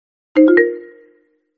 Notificação sonora mais evidente
Ajustamos o som de alerta para mensagens e novos atendimentos, tornando-o mais longo e com um volume mais alto. Esse ajuste facilita a identificação dessas notificações em ambientes movimentados ou com maior fluxo de interações.
NovaNotificação.mp3